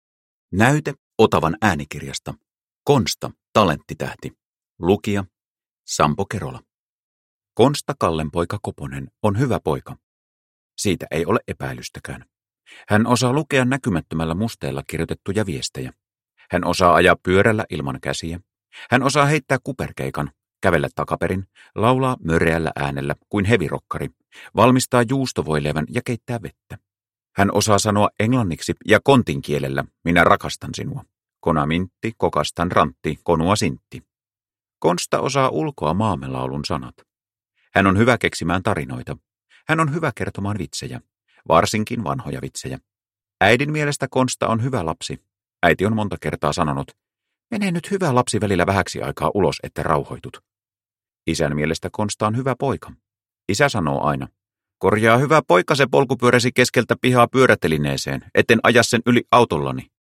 Konsta, talenttitähti – Ljudbok – Laddas ner